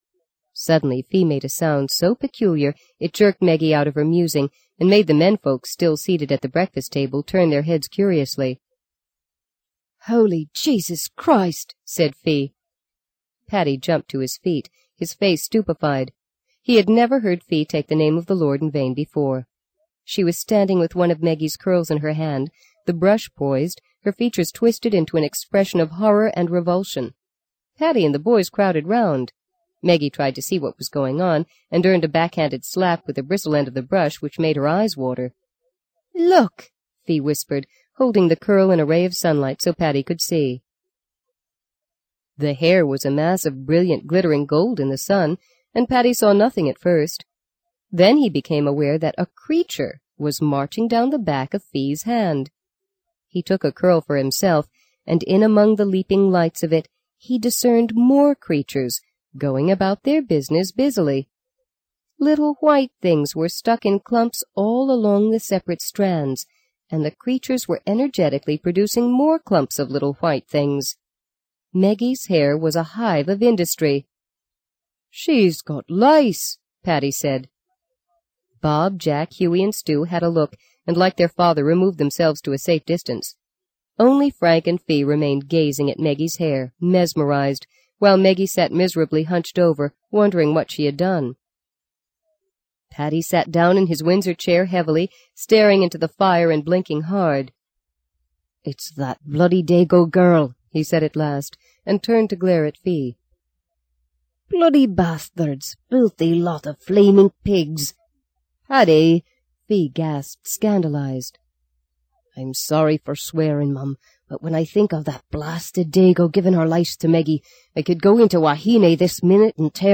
在线英语听力室【荆棘鸟】第二章 19的听力文件下载,荆棘鸟—双语有声读物—听力教程—英语听力—在线英语听力室